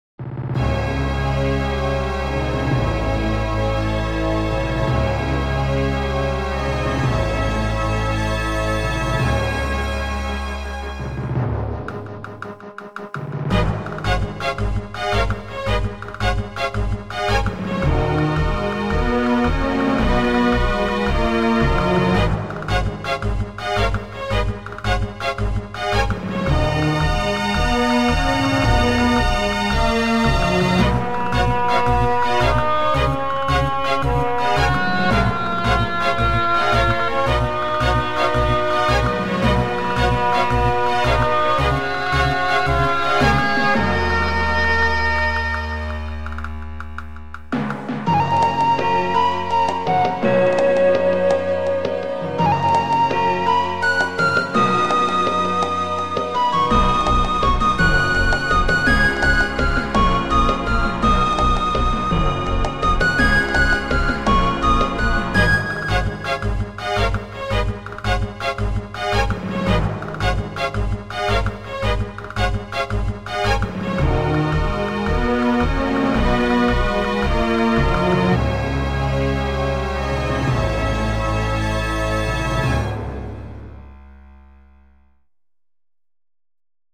orchestral samples make me write completely different-sounding songs, somehow